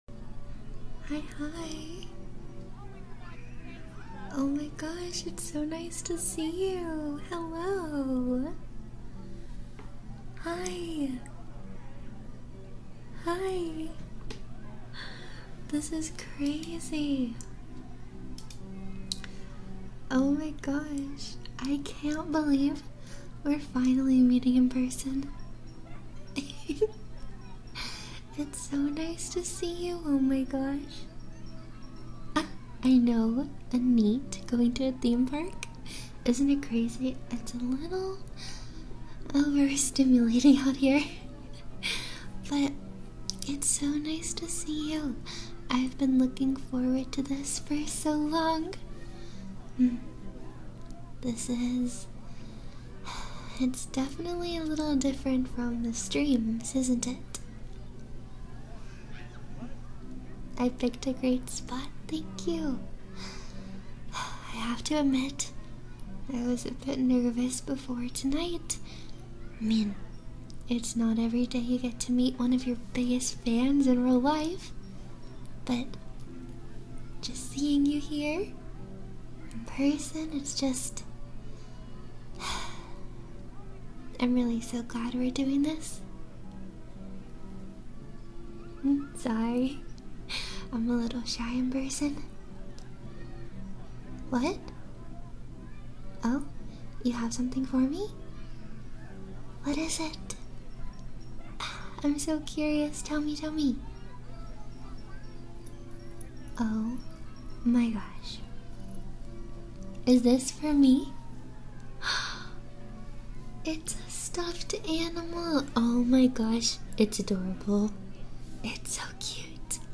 This was a script I filled live on my Twitch channel, I wanted to reupload just the short scripted portion of the stream here!
♡ For the best experience please use good quality headphones ♡